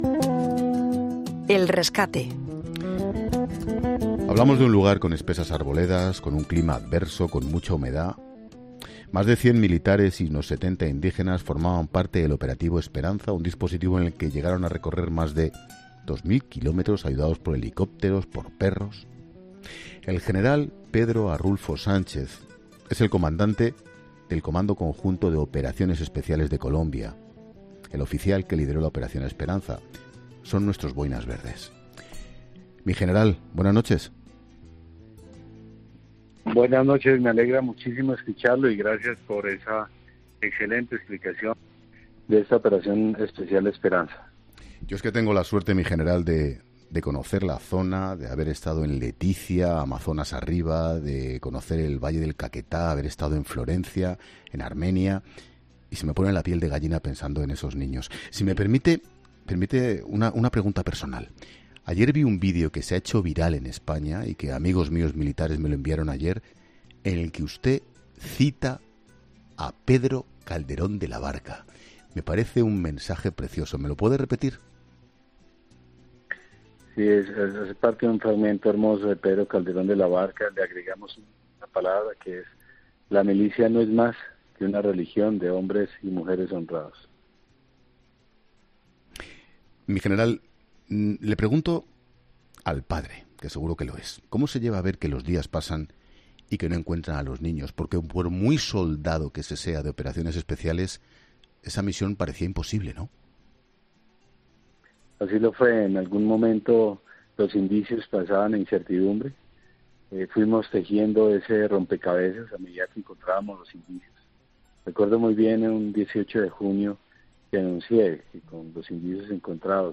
Escucha la entrevista en La Linterna al general Arrulfo Sánchez, líder de la 'Operación Esperanza'